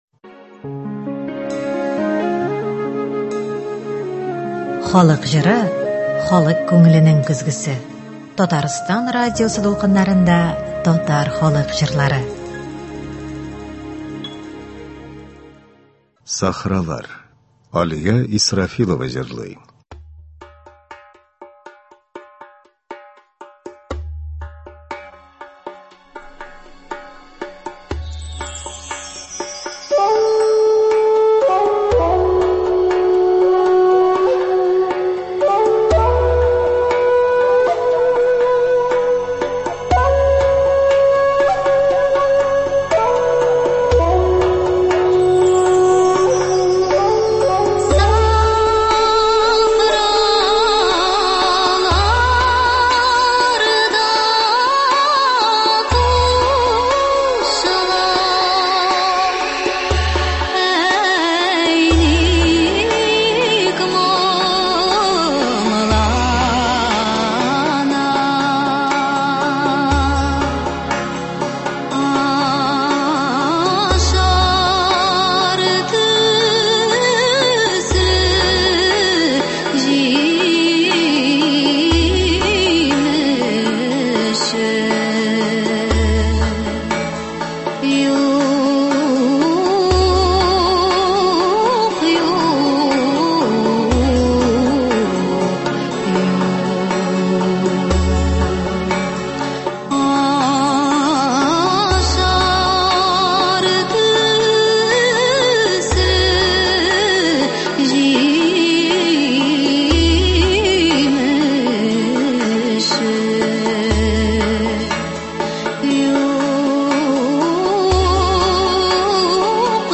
Татар халык көйләре (02.03.24)